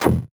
CS_VocoBitB_Hit-08.wav